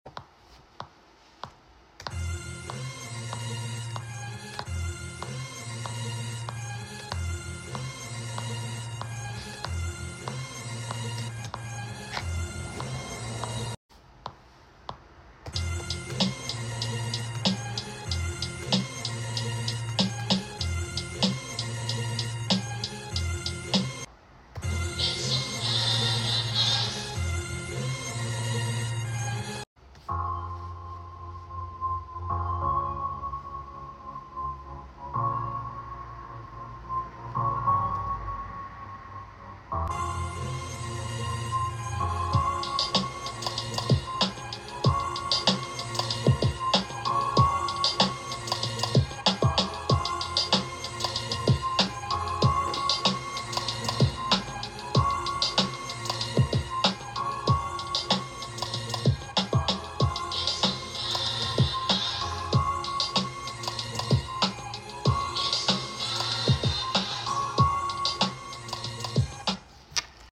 These 1970s vinyl samples have lots of filtering work, was able to use AI to kill off most of the “dust” noise and work on low & high ends manually. Nice boom-bap feel to it!